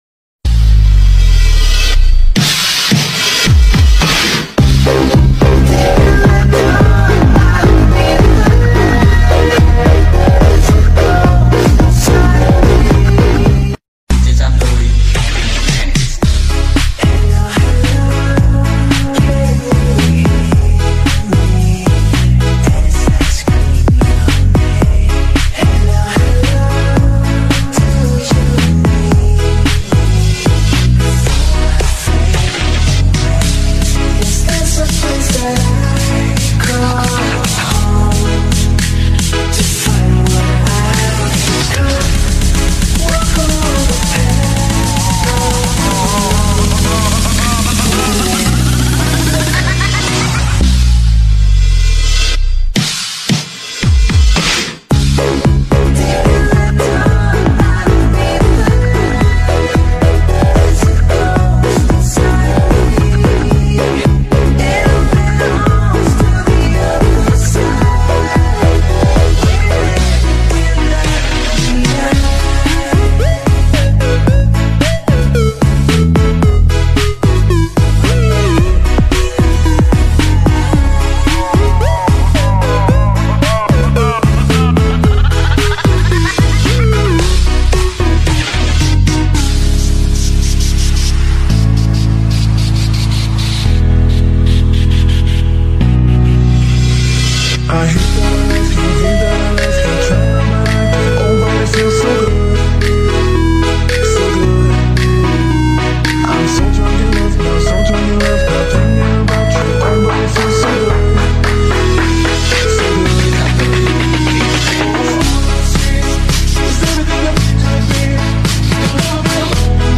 Dj Santuy Slow Fullbass